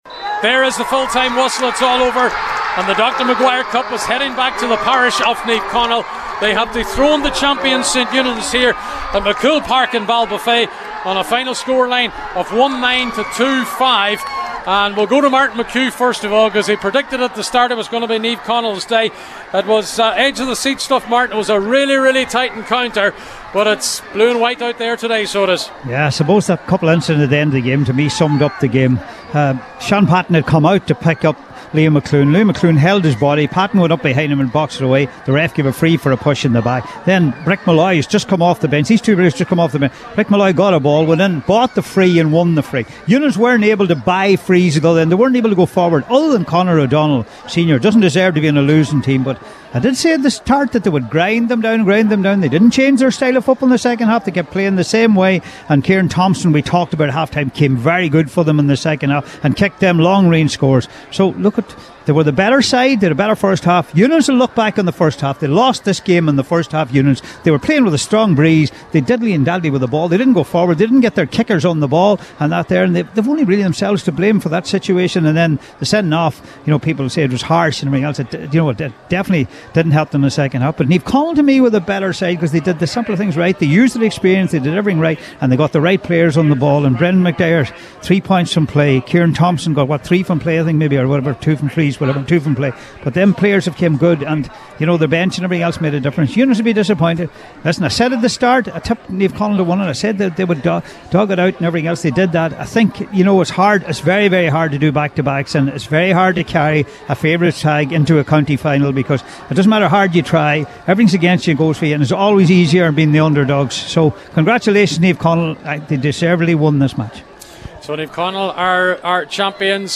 full time report…